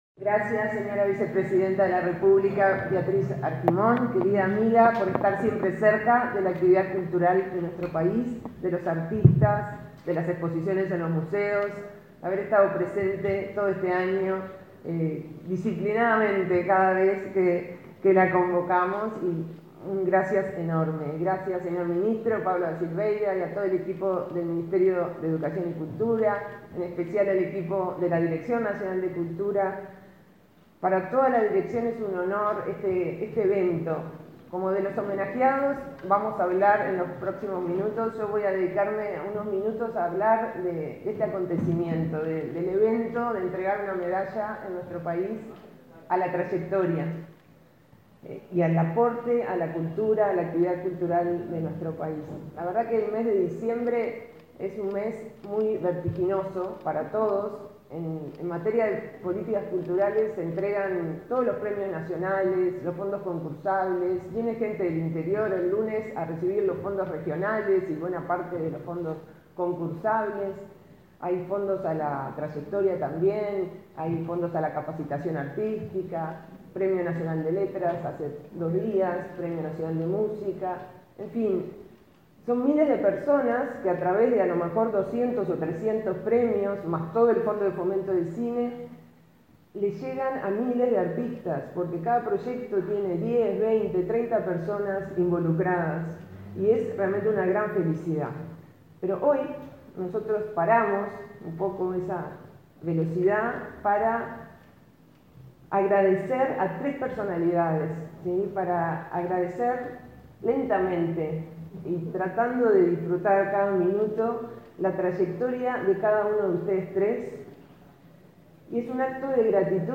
Palabra de autoridades del Ministerio de Educación y Cultura 16/12/2022 Compartir Facebook X Copiar enlace WhatsApp LinkedIn La directora nacional de Cultura, Mariana Wanstein, y el ministro Pablo da Silveira, participaron del acto del entrega de las medallas Delmira Agustini, realizado este viernes 16 en el Palacio Taranco de Montevideo.